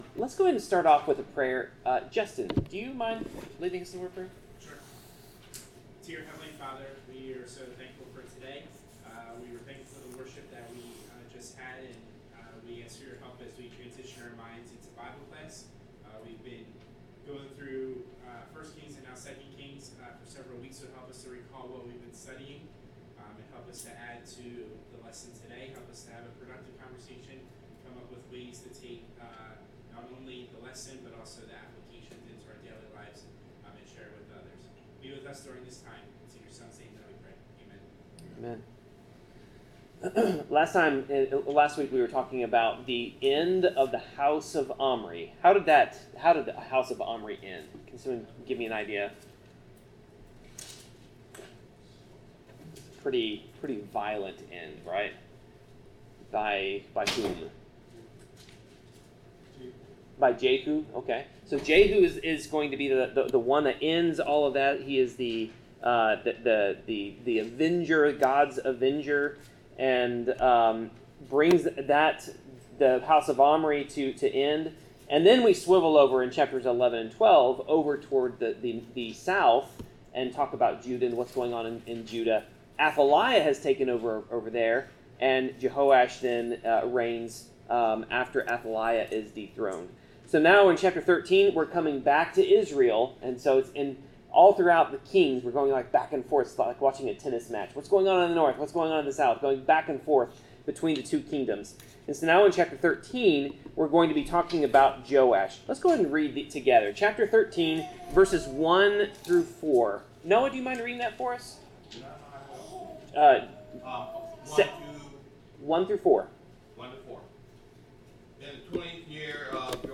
Bible class: 2 Kings 13-14
Service Type: Bible Class